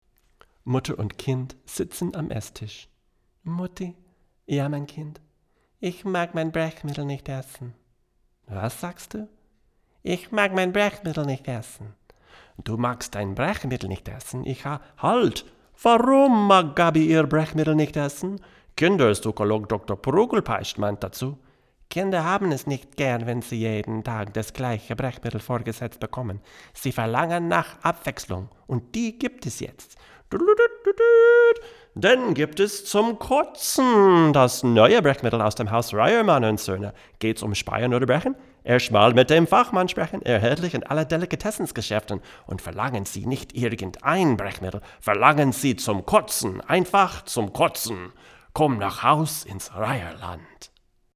Here are 7 quick, 1-take MP3s of this M269 in a large room going into a Audient Black mic preamp and into a Sony PCM D1 flash recorder, with MP3s made from Logic. These tracks are just straight signal with no additional EQ, compresson/limiting or effects:
M269 + AUDIENT BLACK PRE
VOICE OVER: